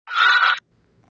Hungry Hippos Scream Botón de Sonido
Animal Sounds Soundboard147 views